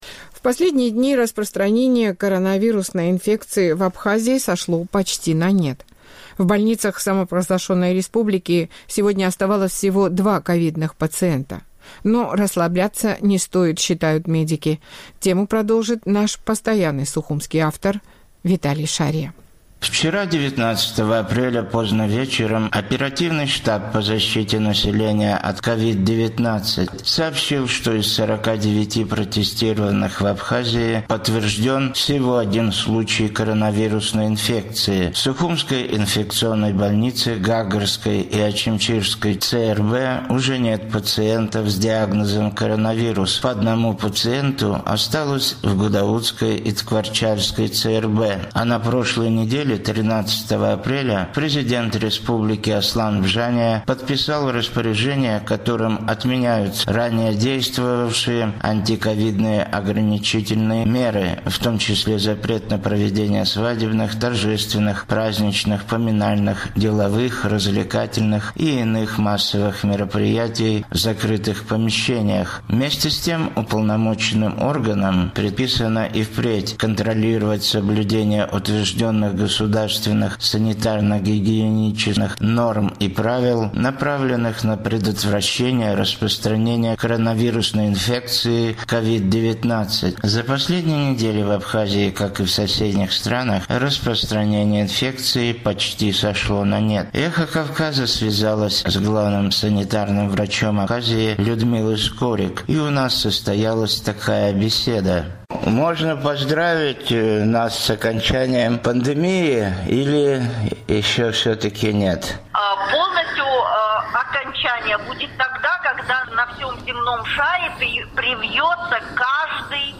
«Эхо Кавказа» связалось с главным санитарным врачом Абхазии Людмилой Скорик, и у нас состоялась такая беседа: